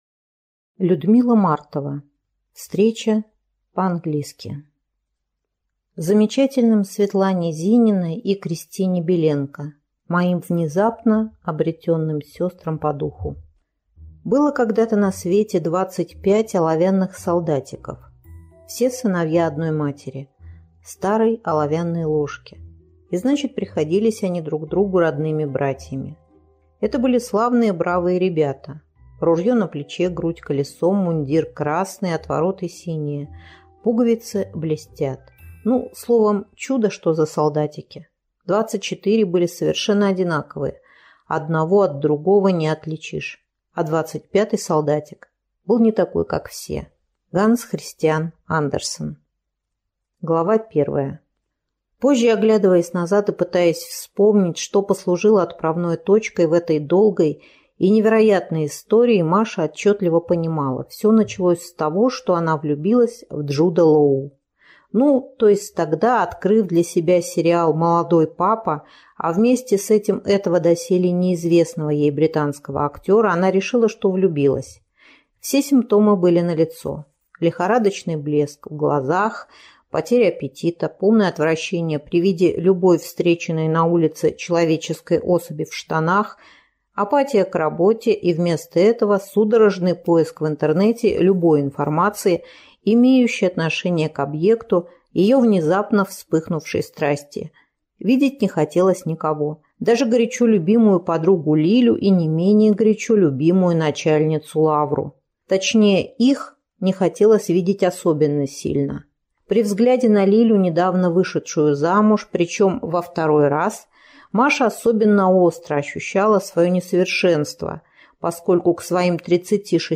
Аудиокнига Встреча по-английски | Библиотека аудиокниг